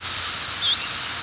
Scarlet Tanager nocturnal
presumed Scarlet Tanager nocturnal flight call